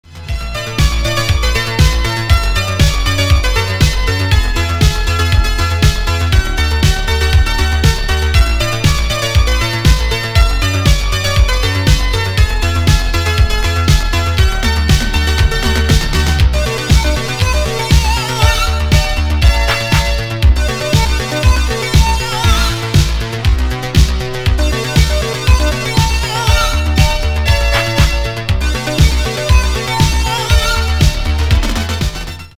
• Качество: 320, Stereo
поп
диско
dance
электронная музыка
спокойные
без слов
дискотека 80-х
итало-диско